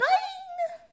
toadette_boing.ogg